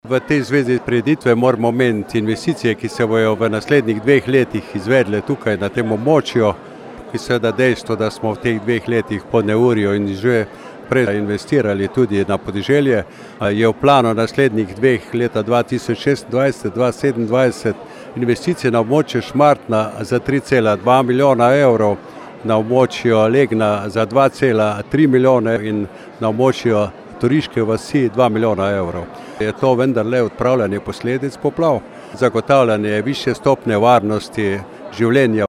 Podžupan Mestne občine Slovenj Gradec dr. Peter Pungartnik je pojasnil, koliko milijonov bo v naslednjih letih šlo v podeželje: